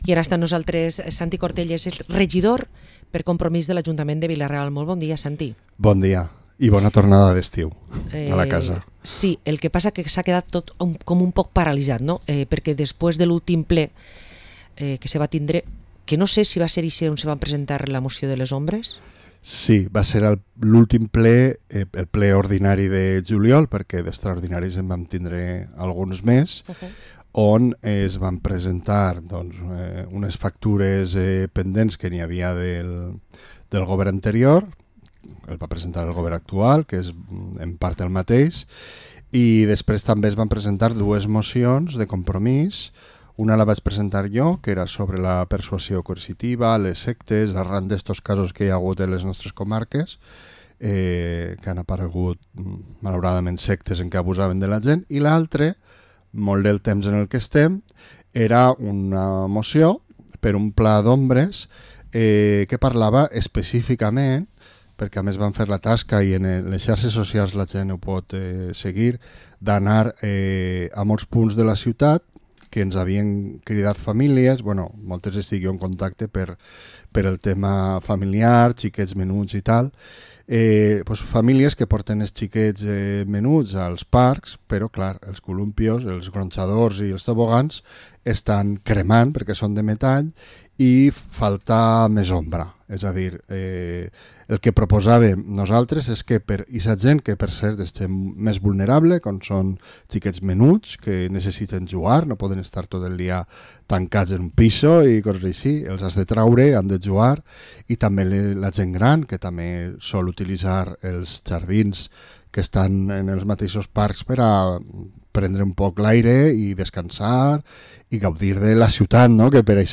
Entrevista a Santi Cortells, regidor de Compromís per Vila-real